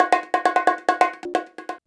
133BONG04.wav